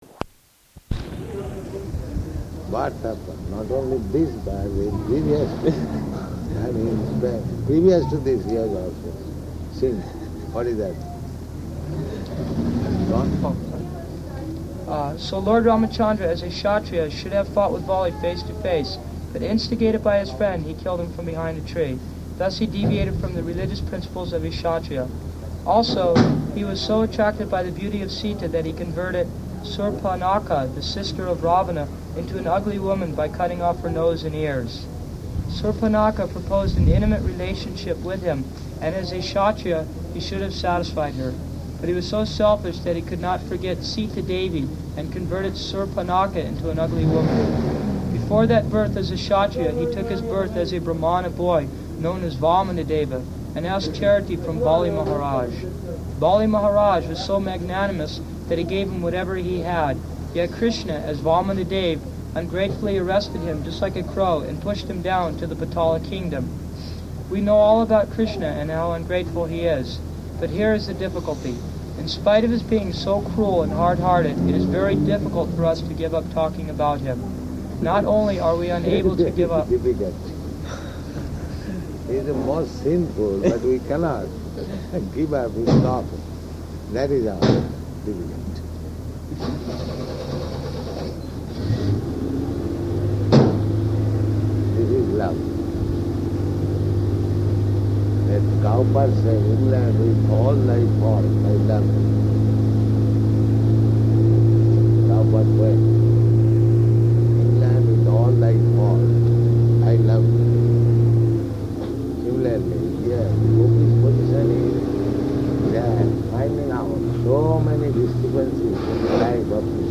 Type: Conversation
Location: Los Angeles